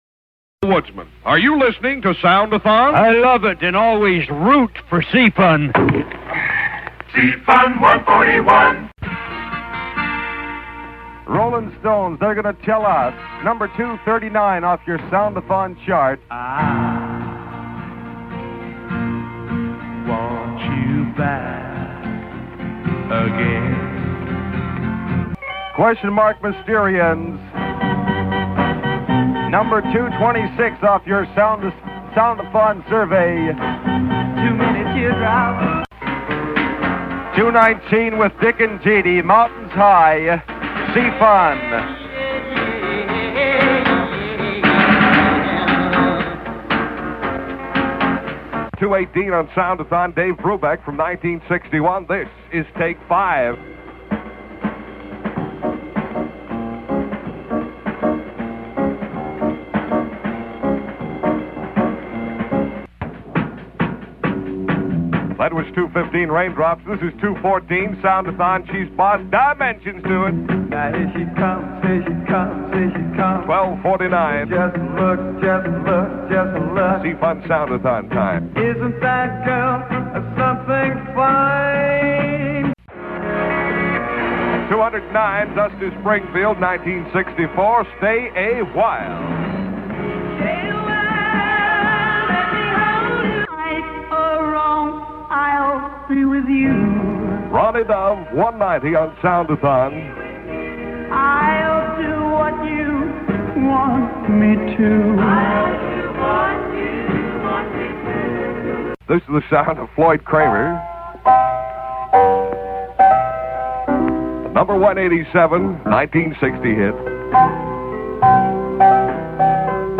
The C-FUN Good Guys introduce the songs in these audio clips recorded
directly from the radio during the actual event in January 1967.
DJs heard in this Montage: